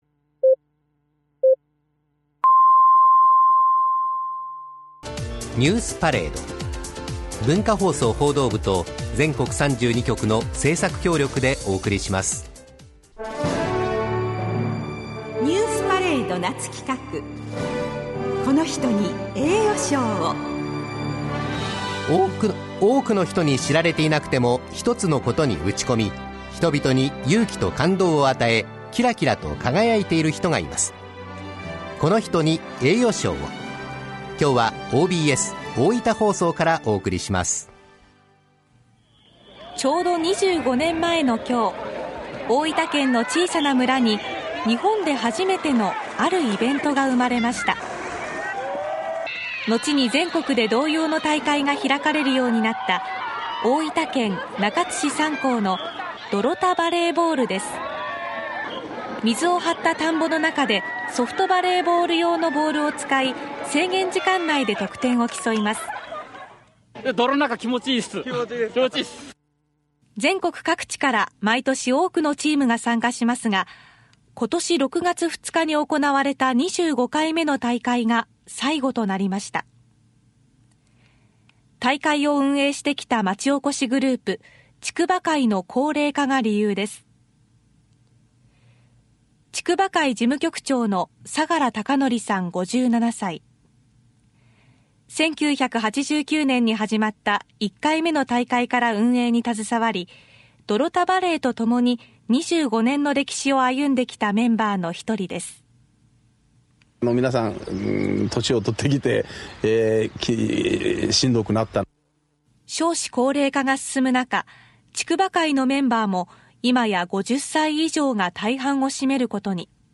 泥田バレーが文化放送（ラジオ）で全国放送されました
２５年目の平成２５年８月６日（火）午後５時〜午後５時１５分の文化放送のラジオ番組「ニュースパレード」の中の“この人に栄誉賞を！”のコーナーで泥田バレーが３２局ネットで全国放送されました。
■放送内容はこちら（録音）　　→